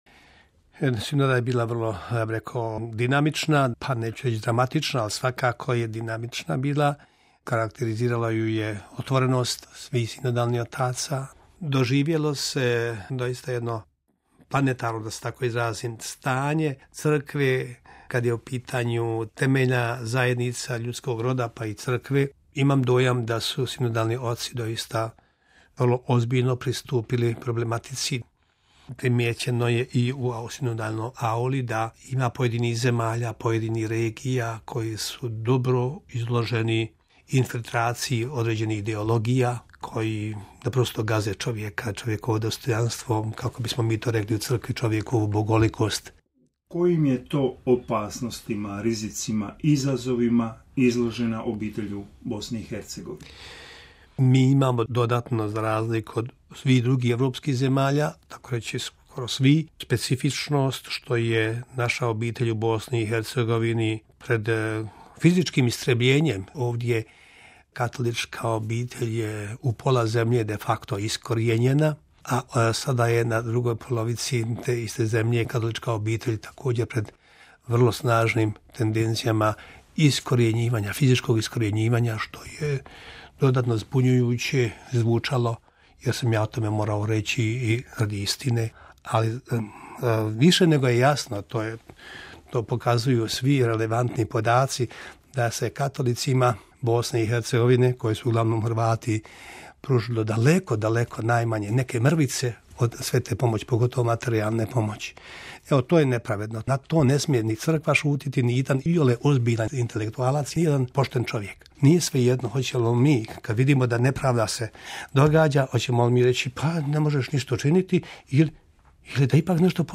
Razgovor s biskupom Franjom Komaricom
U proteklim danima razgovarali smo s msgr. Franjom Komaricom, banjolučkim biskupom, koji je sudjelovao na netom završenoj izvanrednoj sinodi o obitelji. O tome kako je doživio sinodu, o problemima obitelji u BiH te o njegovim mislima o novoproglašenom blaženiku, papi Pavlu VI. kao i o papi Franji, poslušajte u prilogu koji slijedi.